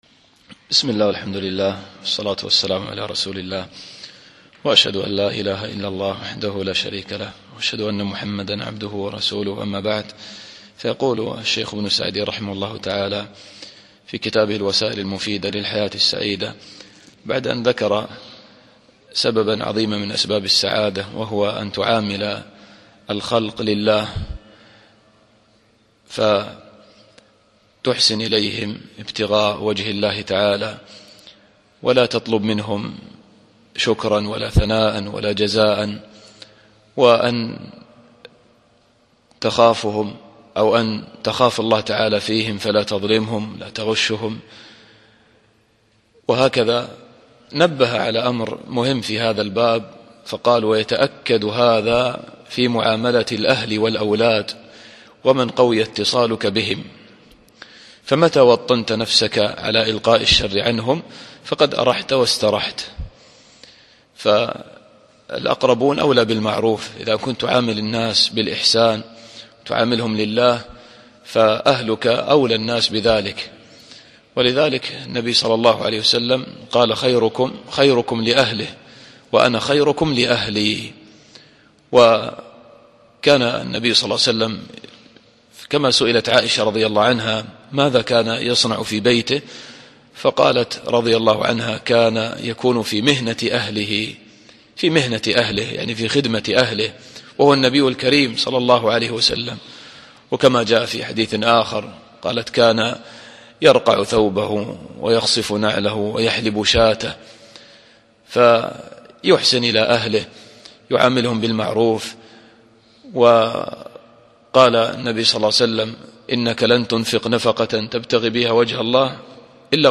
الدرس العشرون